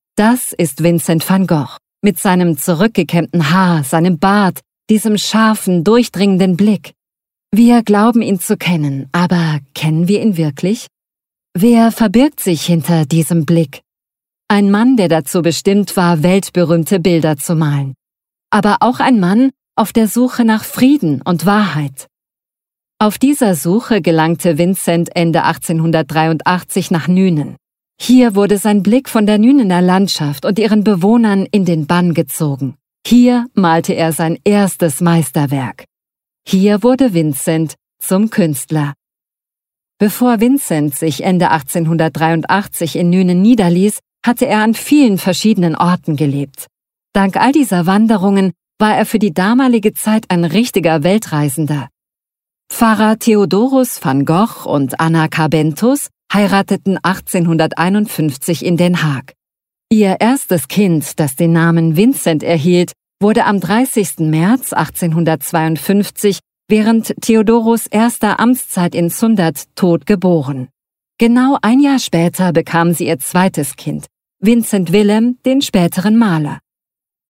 Native voices